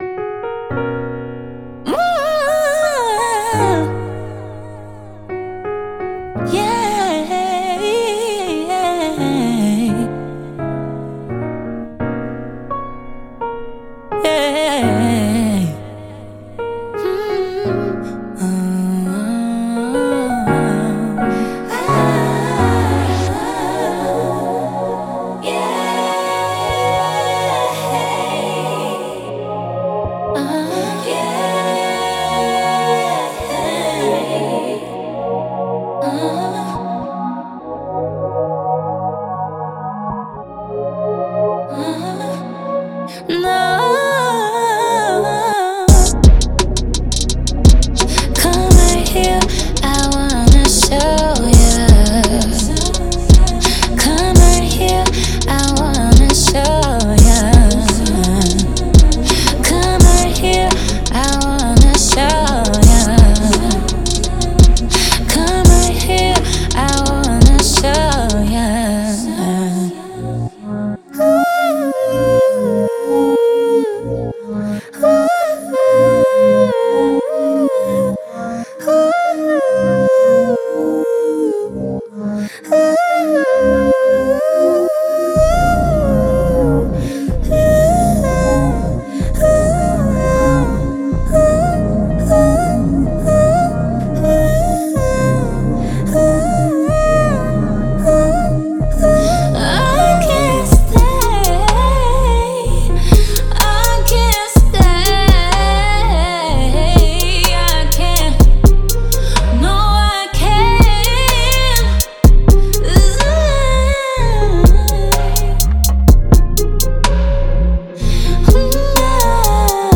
Genre:Soul and RnB
ボーカルコーラス
アドリブ
チョップス
バックグラウンドボーカル
R&Bとポップに最適
ボーナスドラムなども収録